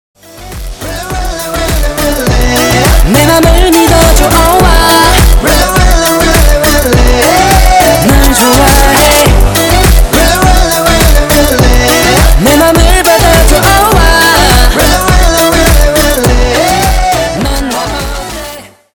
• Качество: 320, Stereo
ритмичные
мужской вокал
remix
мощные басы
K-Pop